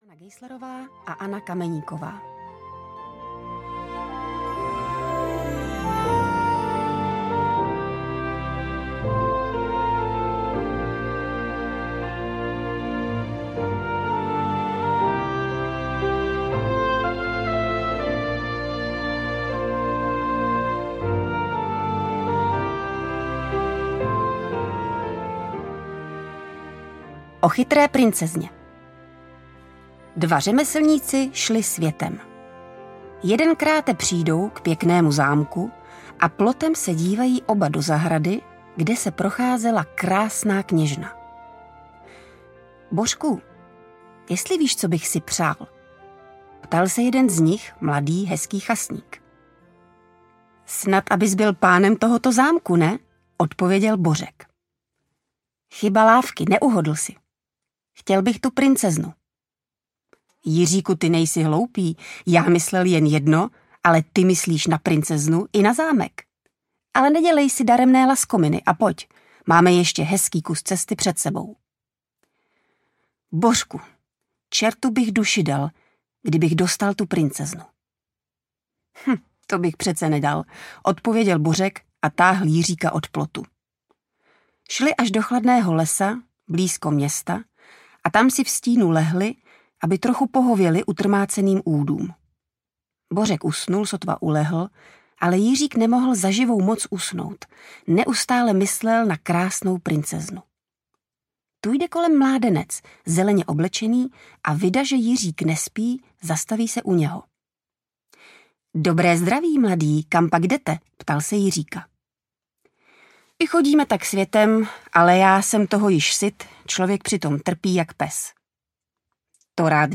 Podivuhodné pohádky audiokniha
Ukázka z knihy